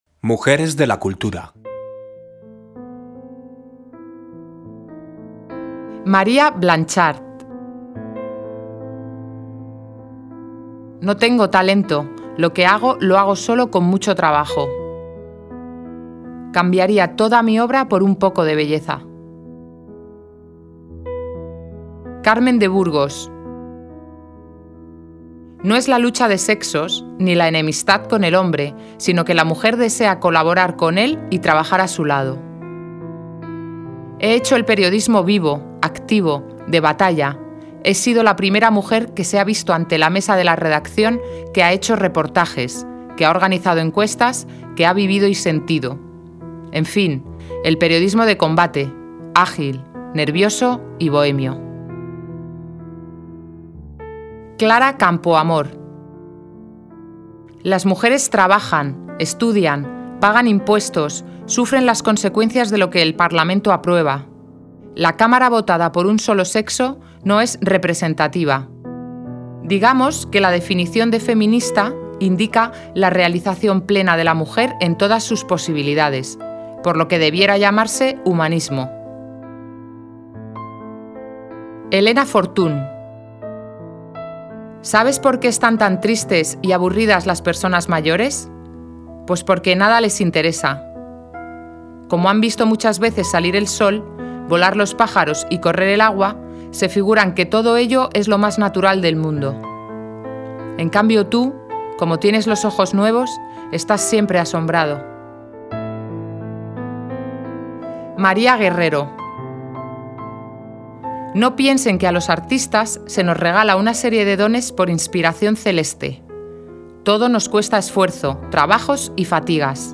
Lectura inicial. Mujeres de la cultura
17_lectura_mujeresdelacultura.mp3